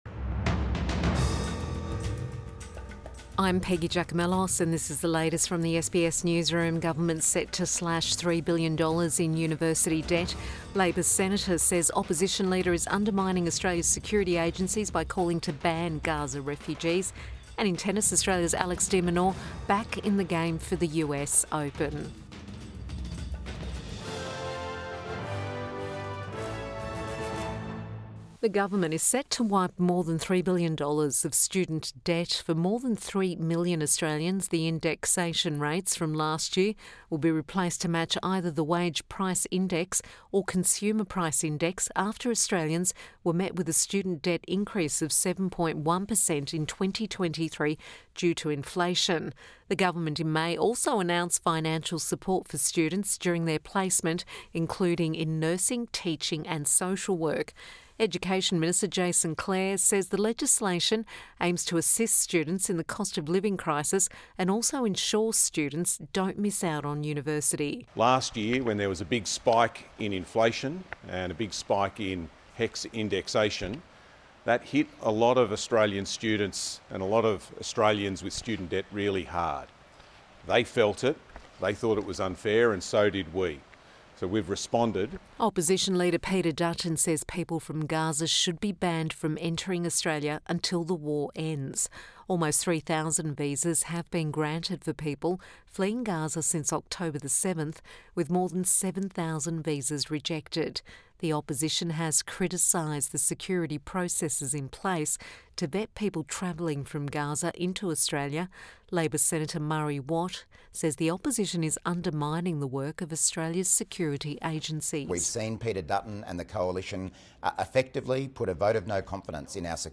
Midday News Bulletin 15 August 2024